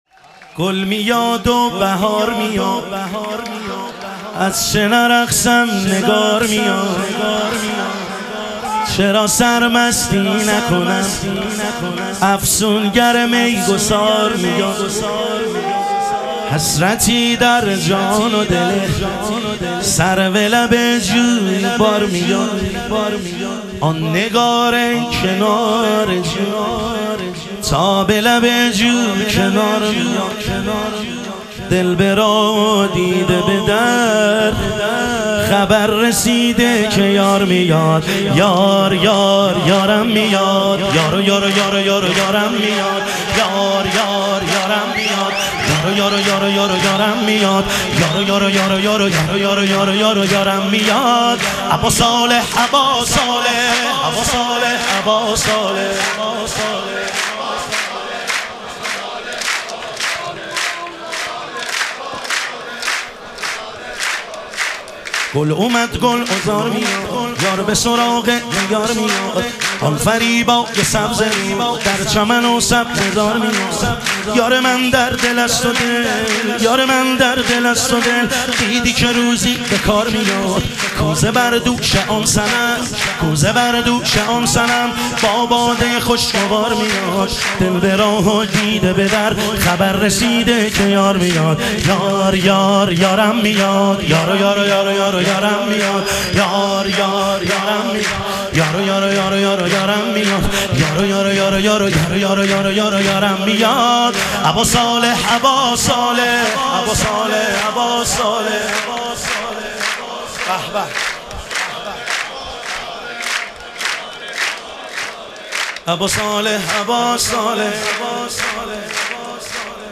ظهور وجود مقدس حضرت مهدی علیه السلام - شور